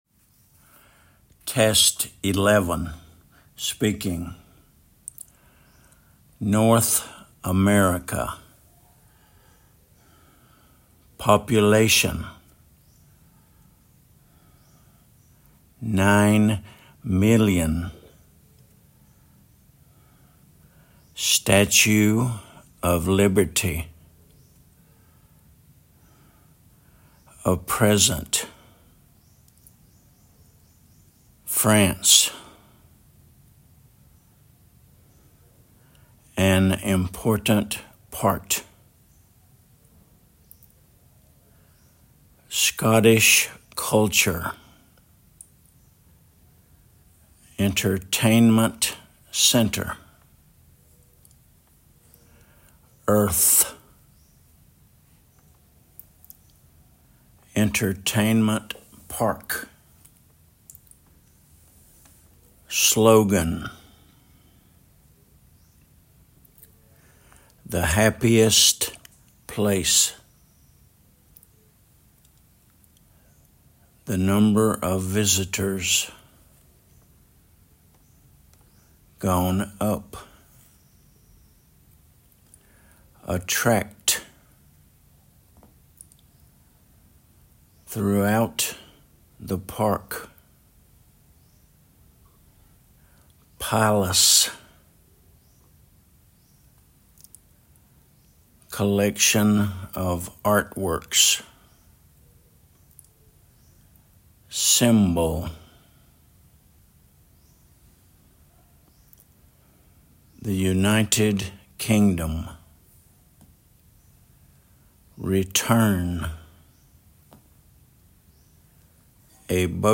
North America /nɔːθ əˈmɛrɪkə/
population /ˌpɒpjʊˈleɪʃən/
Statue of Liberty /ˈstætʃuː əv ˈlɪbəti/
Scottish culture /ˈskɒtɪʃ ˈkʌltʃə/
historic attractions /hɪsˈtɒrɪk əˈtrækʃənz/